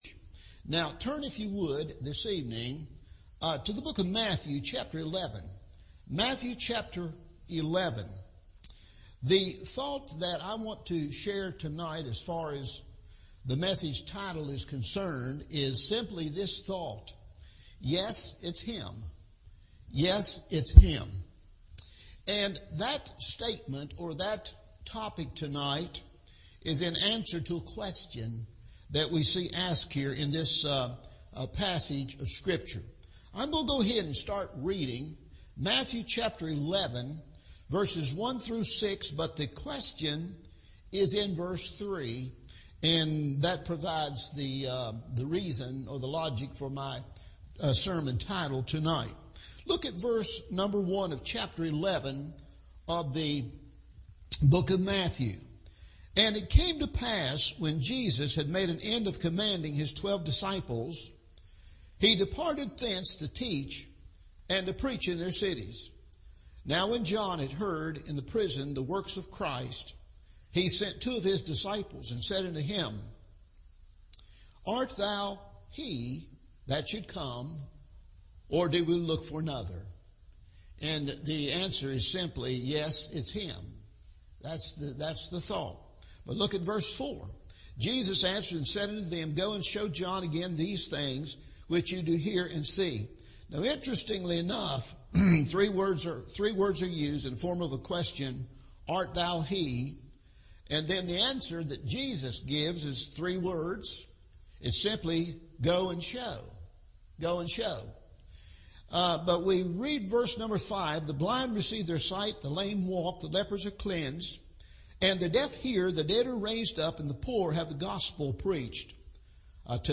Yes, It’s Him – Evening Service